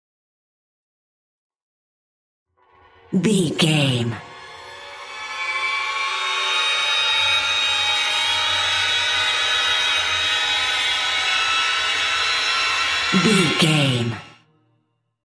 In-crescendo
Thriller
Atonal
Slow
scary
ominous
dark
suspense
haunting
eerie
stinger
short music instrumental
horror scene change music